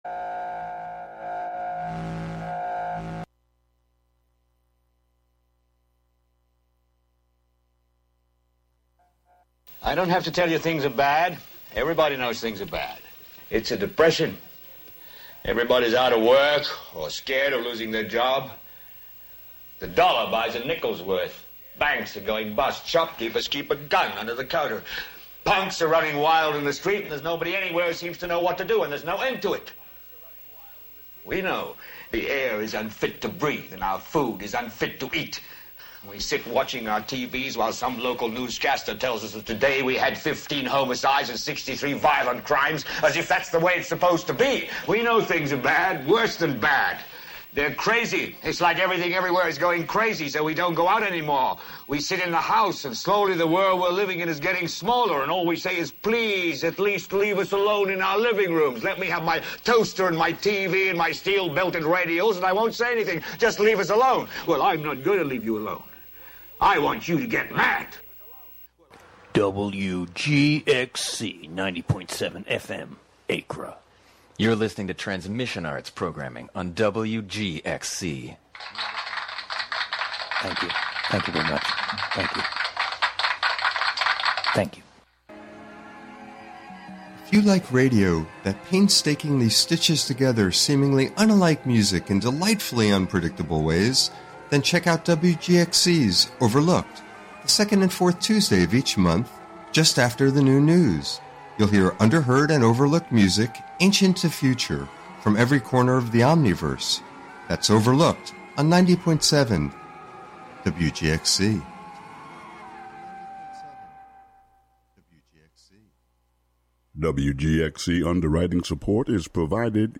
Counting down ten new sounds, stories, or songs, "American Top 40"-style. Usually the top ten is recent songs, but sometimes there are thematic countdowns, or local music-themed shows.